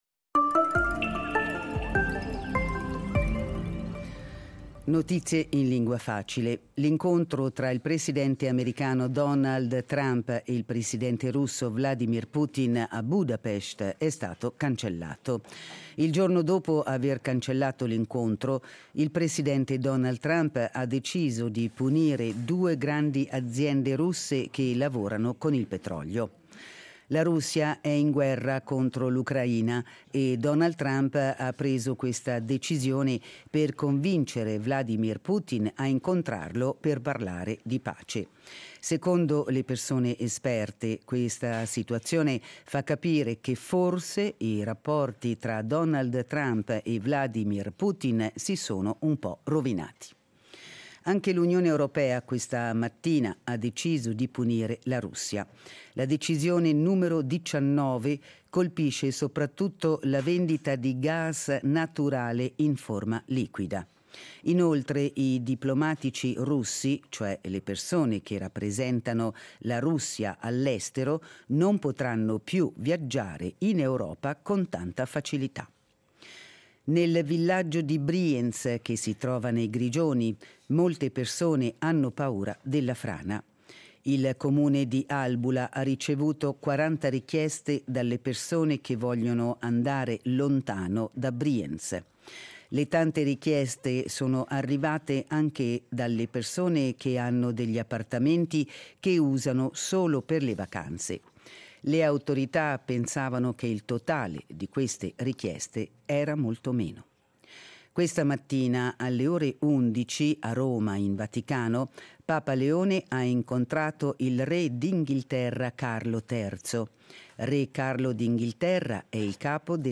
Notizie in lingua facile